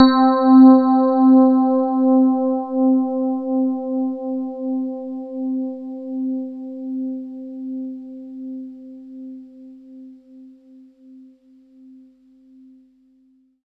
85 RHODES -R.wav